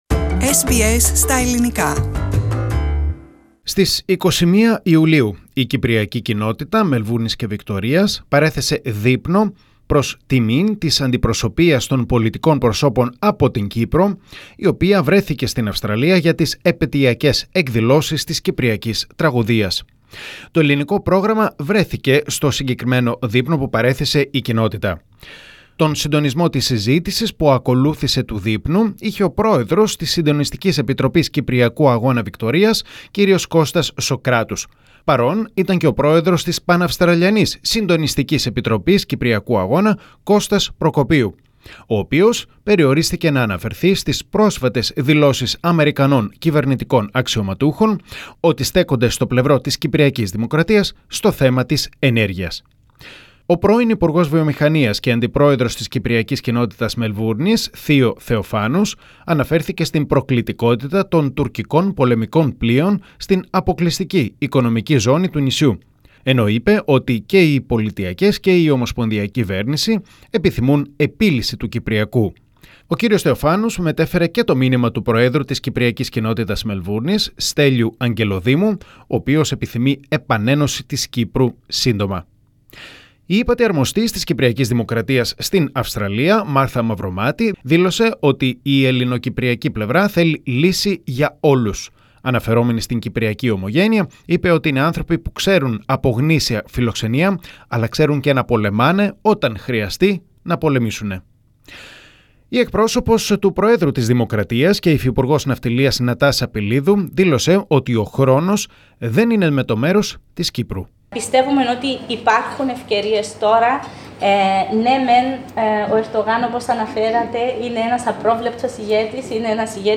Το Ελληνικό Πρόγραμμα βρέθηκε στο δείπνο που παρέθεσε η Κοινότητα.